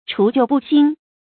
除舊布新 注音： ㄔㄨˊ ㄐㄧㄨˋ ㄅㄨˋ ㄒㄧㄣ 讀音讀法： 意思解釋： 布：安排；展開。